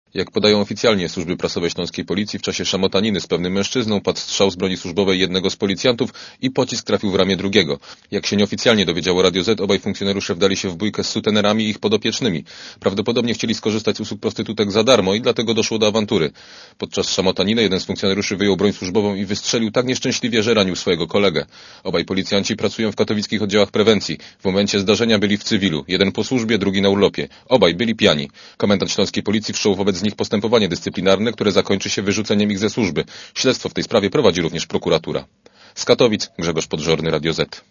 Relacja reportera Radia Zet (148Kb)